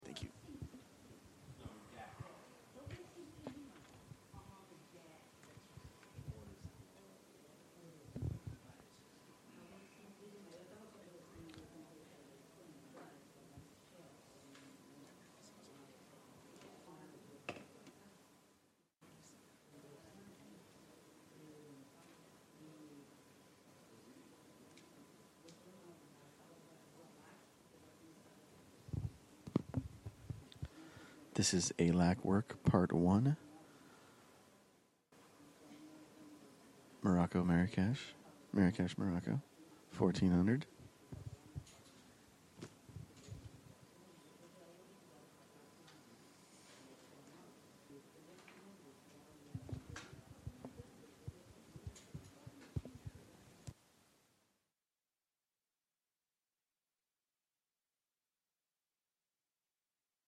This event took place on 8 March 2016 from 14:00-16:00 +00 at ICANN Public Meetings in the Roseraie room.